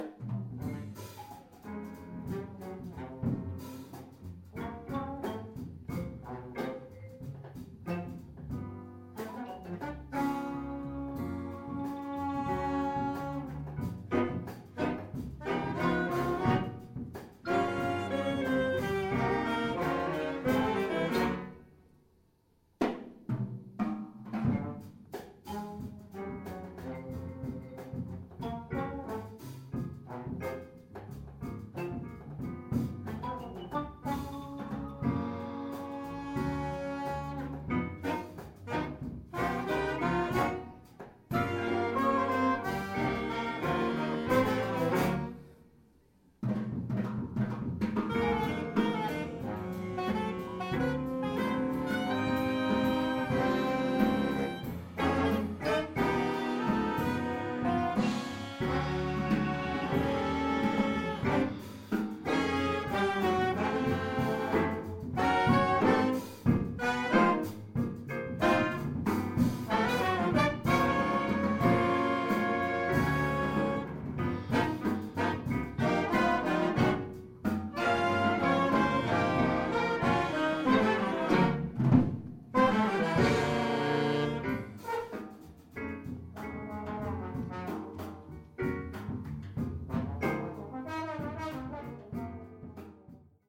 medium tempo, funk beat